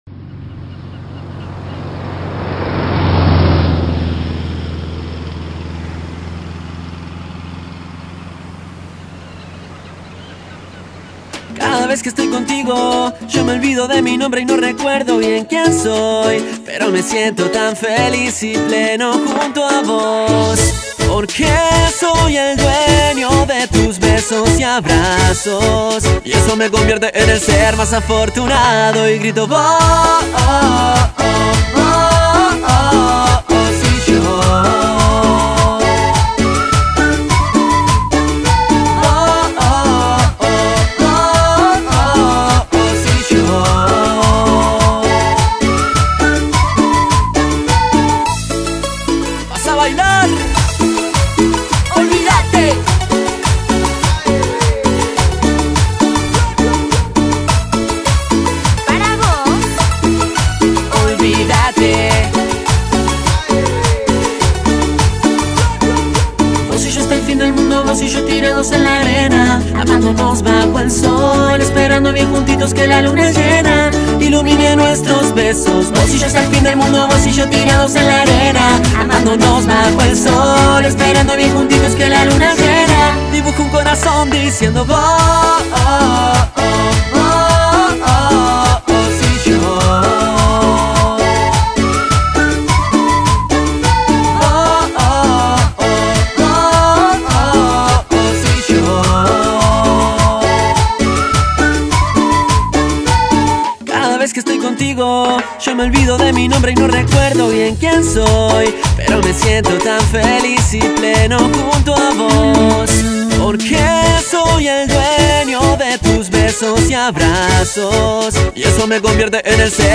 Carpeta: Cumbia y + mp3